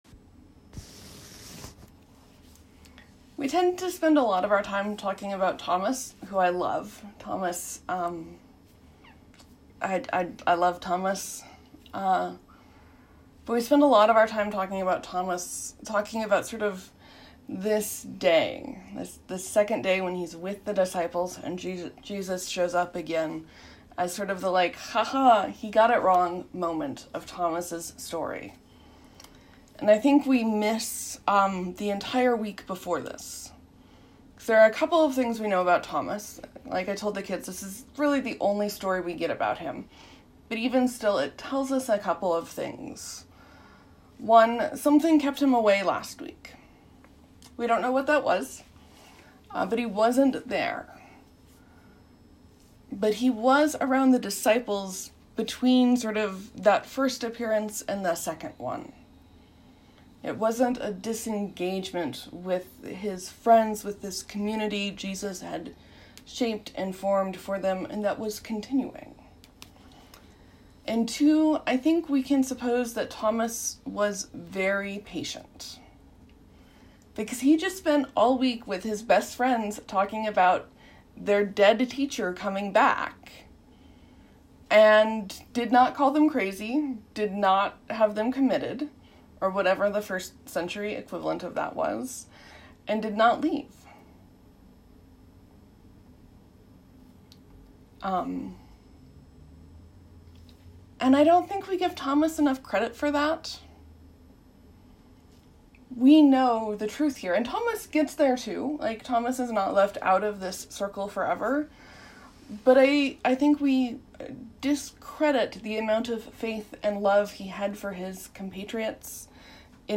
Which, means that for the first time in 6 years I preached on Thomas.